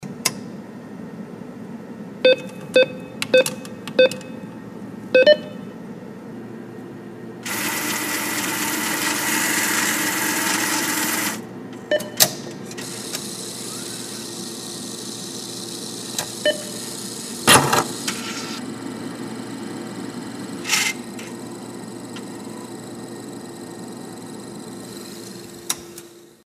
• Качество: высокое
Звук выдачи денег из банкомата